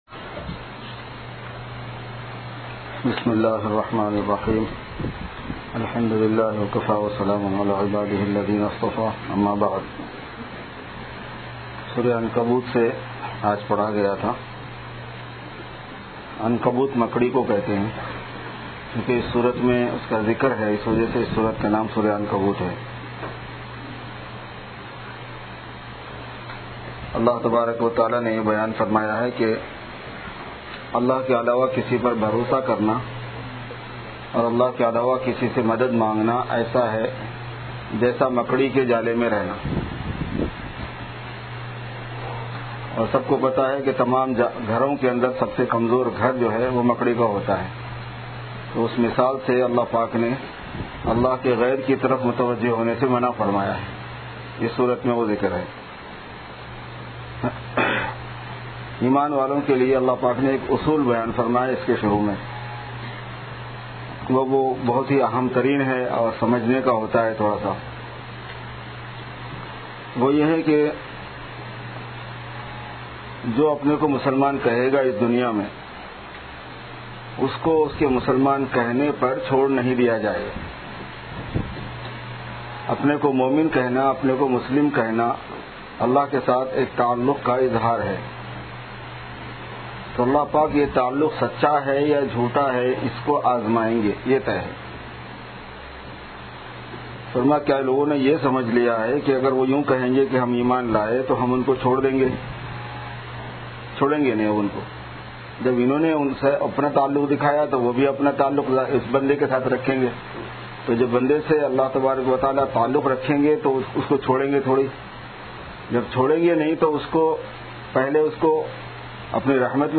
Ramadhan Tafseer 2015-1436:Khatm-e-Qur'an Tilawat and Naat Shareef